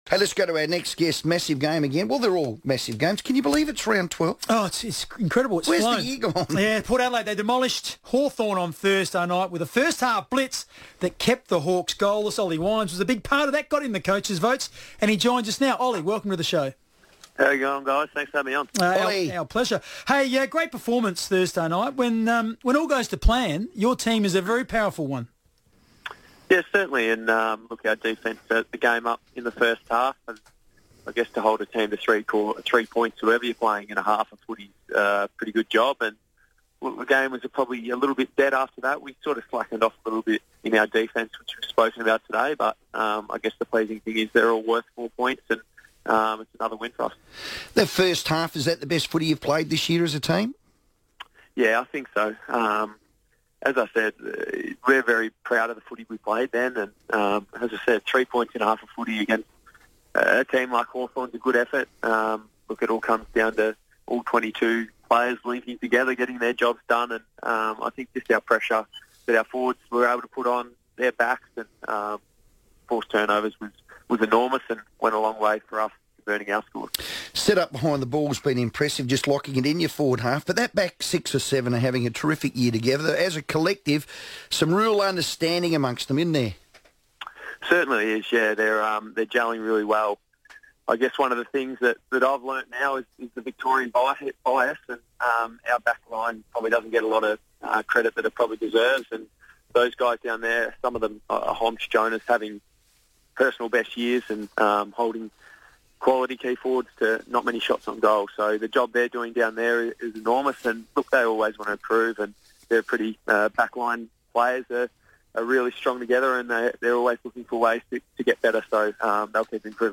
The Port vice-captain talks footy